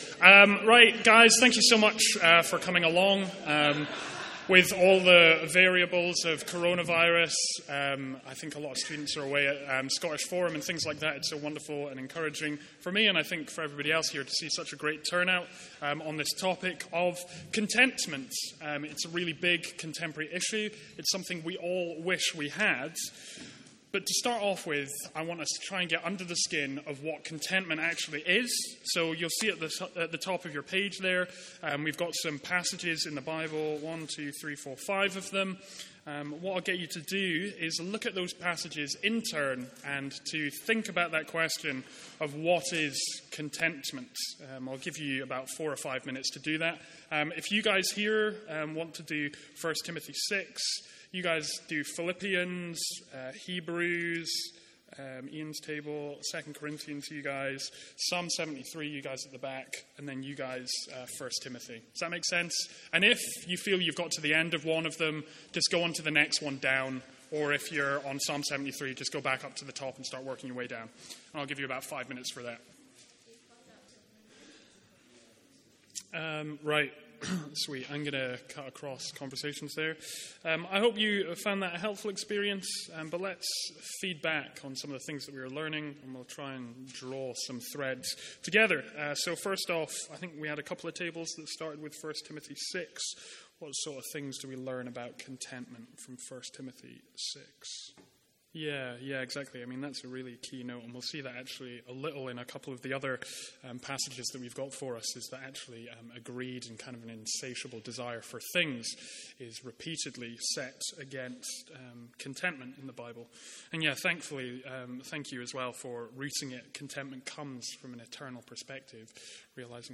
From our fifth Student Lunch of the academic year.